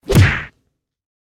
kick.ogg.mp3